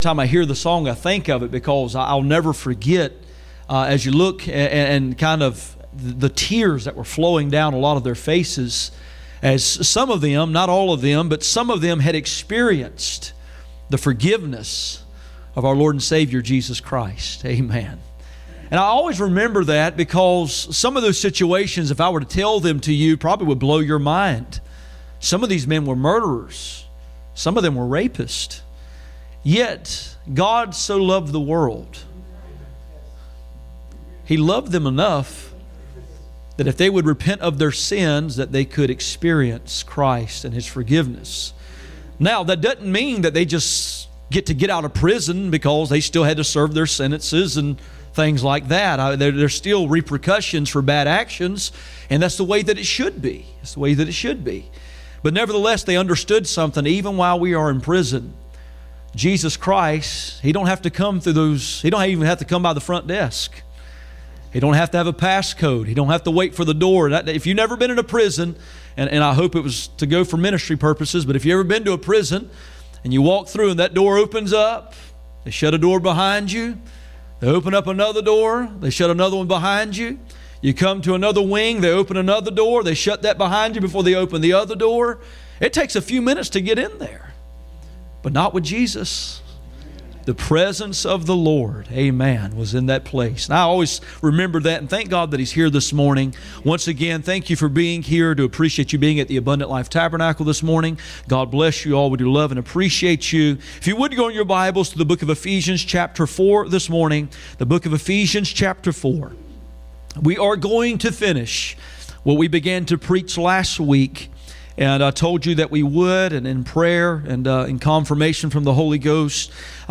Passage: Ephesians 4:23-32 Service Type: Sunday Morning